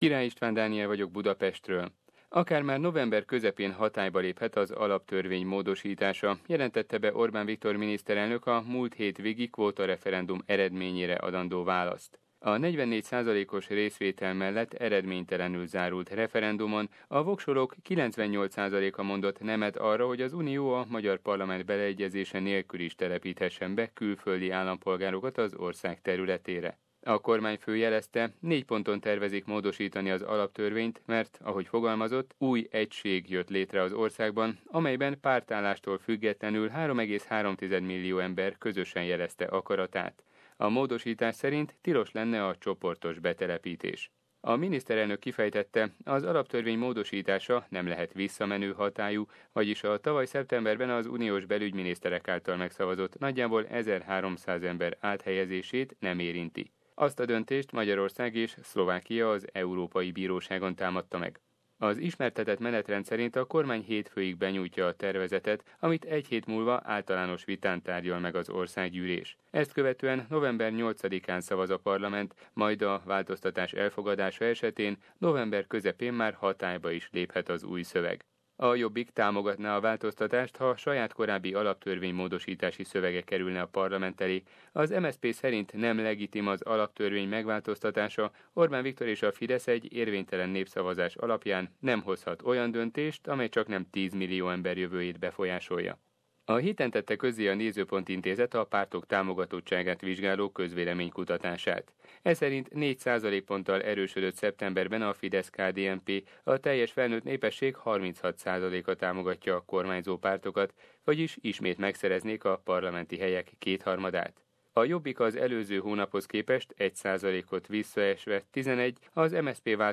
Tudósító jelenti: Rogán Antal helikopterrel repült a Nyírségbe